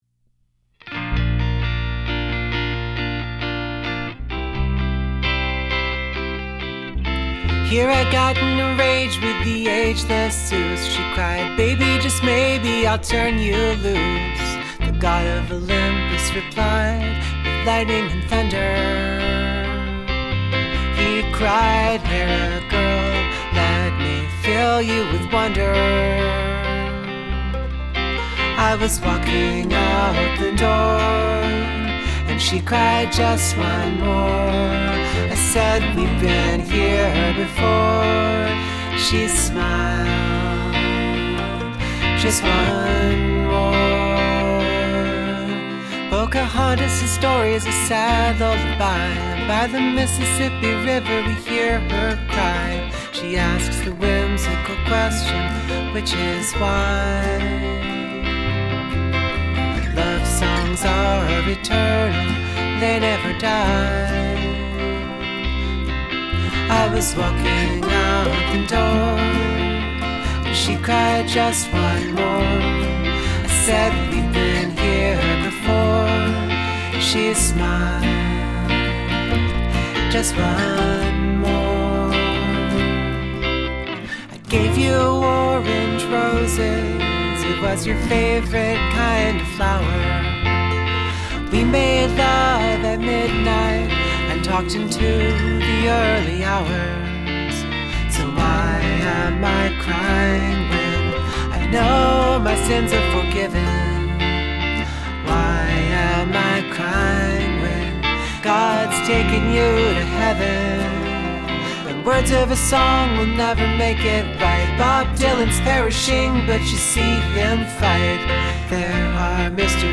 Love your vocal layers.